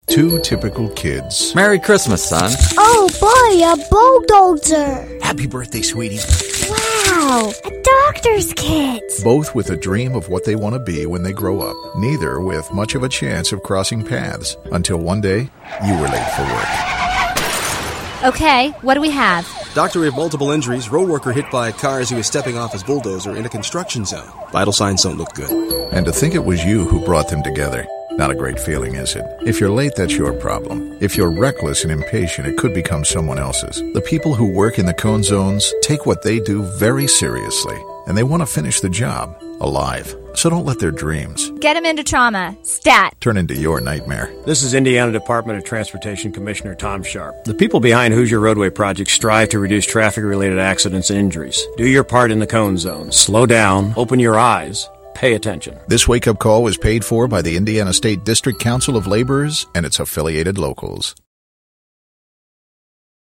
Commercials are on tracks 19 through 25 – some great stuff in both categories!